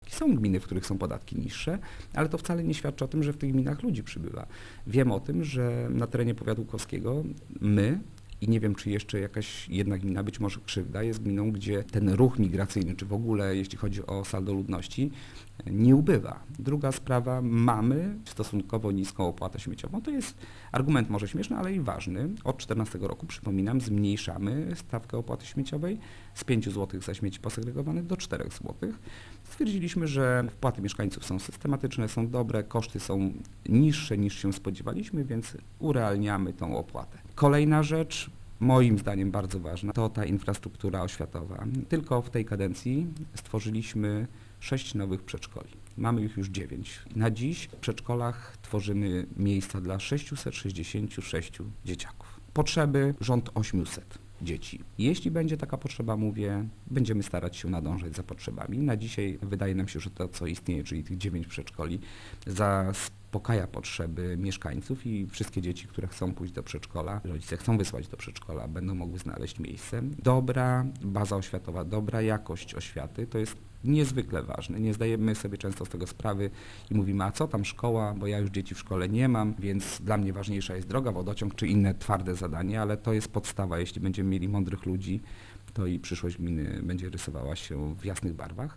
Według wójta Mariusza Osiaka powodem takiej sytuacji jest nie tylko położenie geograficzne w okolicy miasta Łuków, ale także wiele innych argumentów, w tym niskie podatki i opłaty lokalne: